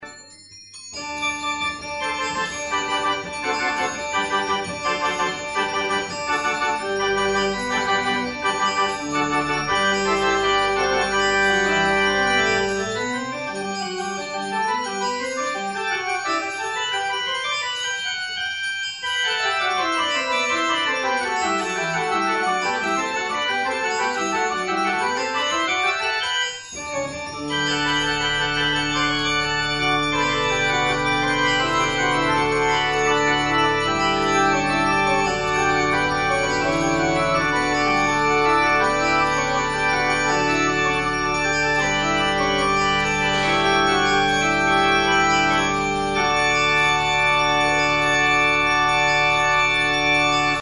Orgel - Olberode
Register: Gedackt 8' +
Rohrflöte 4' + Schwiegel 2'
dann ohne Schwiegel
dann Tutti
Die Orgel steht in der evangelischen Kirche in 36280 - Olberode.